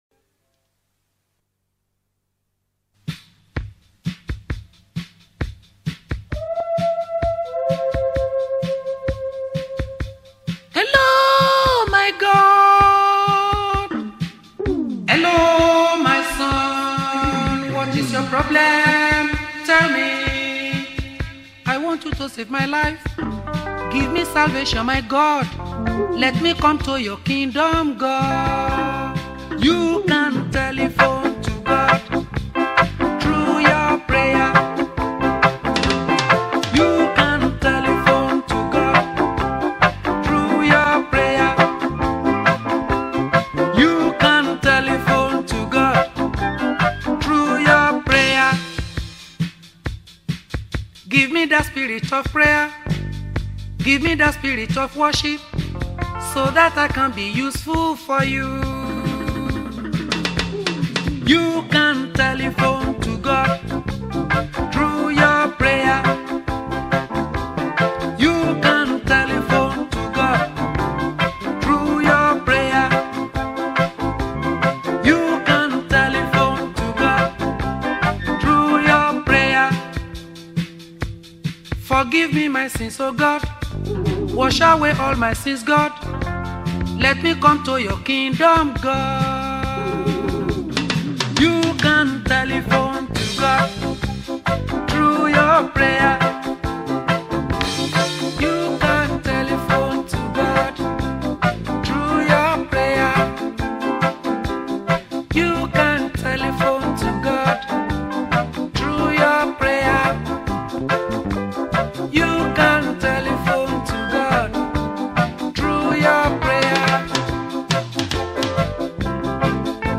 March 28, 2025 Publisher 01 Gospel 0